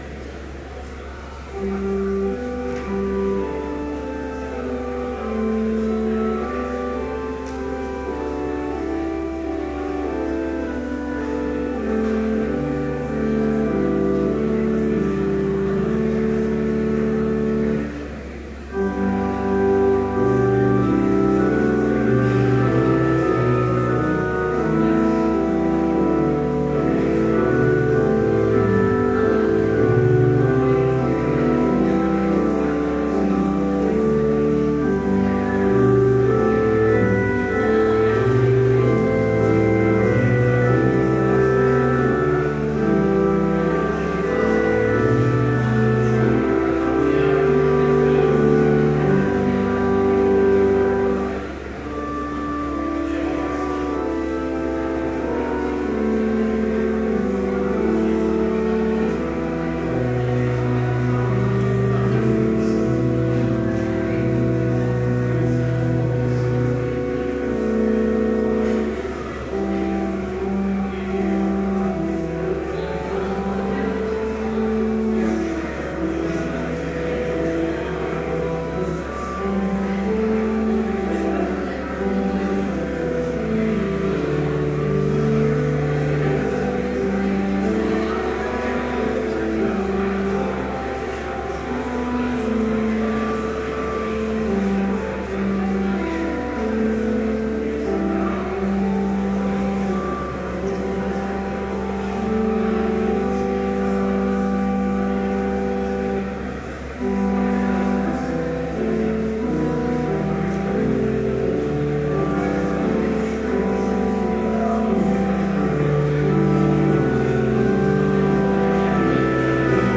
Complete service audio for Chapel - October 23, 2013
Listen Complete Service Audio file: Complete Service Sermon Only Audio file: Sermon Only Order of Service Prelude Hymn 361, vv. 1-4, Faith Is a Living Power from Heaven Reading: Hebrews 11:1-2 Homily Prayer Hymn 361, vv. 5 & 6, We thank Thee… Benediction Postlude Scripture Hebrews 11:1-2 Now faith is the substance of things hoped for, the evidence of things not seen.